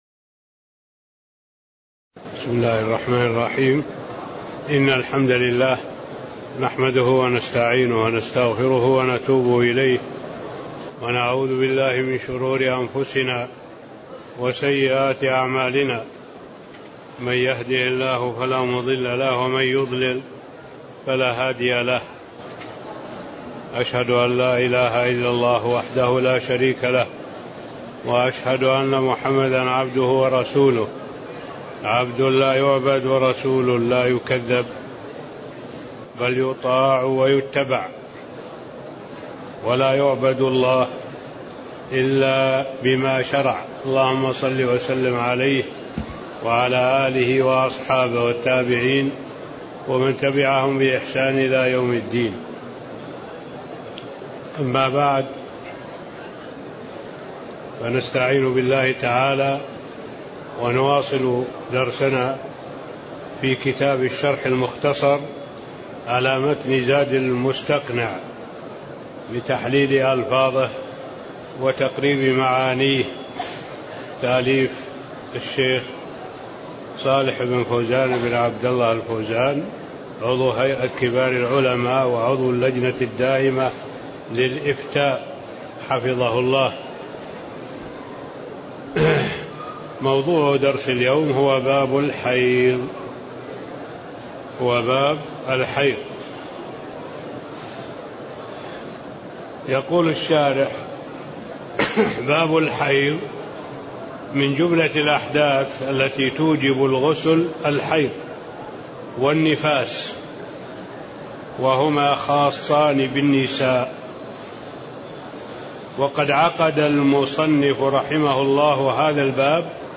تاريخ النشر ١٤ ربيع الثاني ١٤٣٤ هـ المكان: المسجد النبوي الشيخ